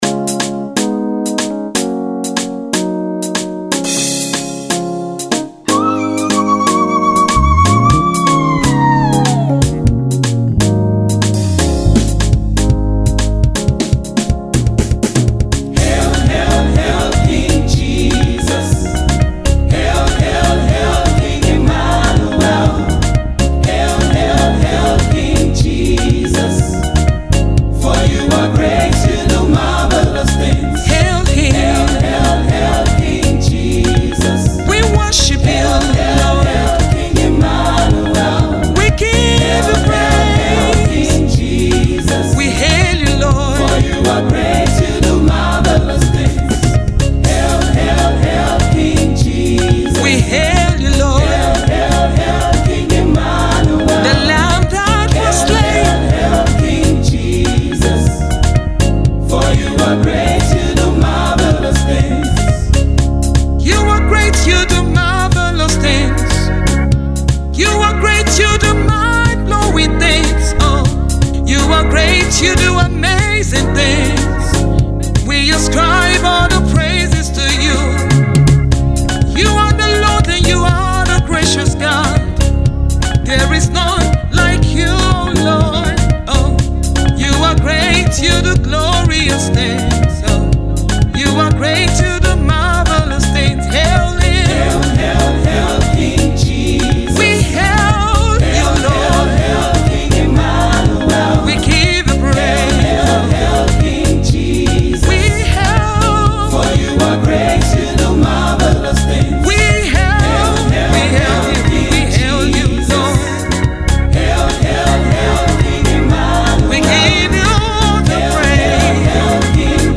captivating and high energy gospel music
This is a gospel music